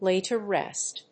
アクセントláy…to rést